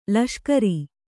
♪ laṣkari